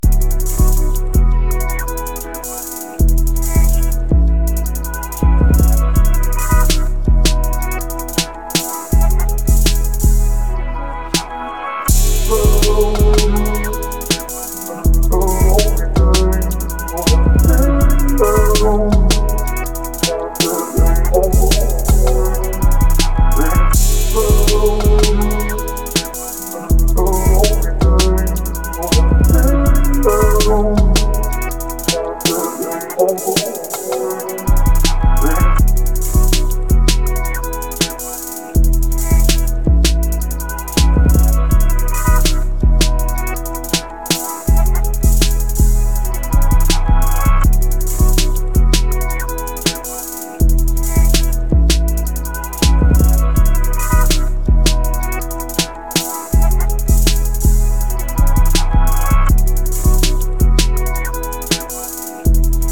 Trap Beat Sync Music Licenses